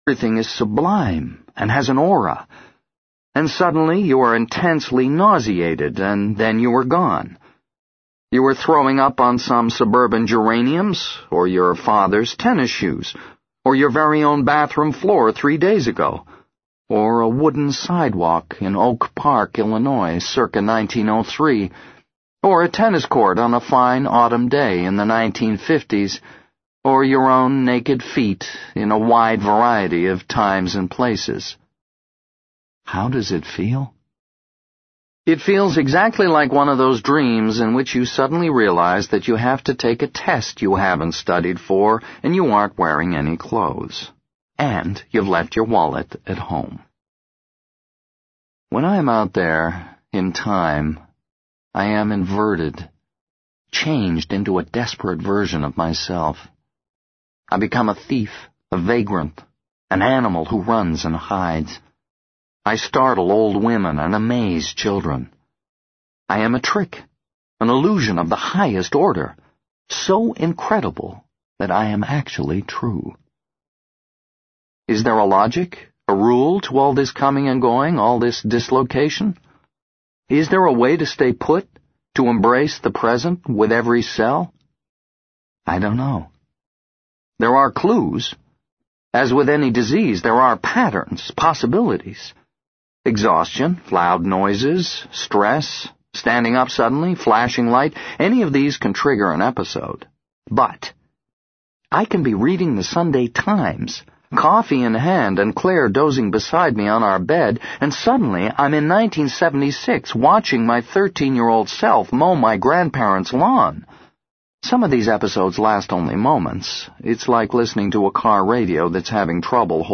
在线英语听力室【时间旅行者的妻子】03的听力文件下载,时间旅行者的妻子—双语有声读物—英语听力—听力教程—在线英语听力室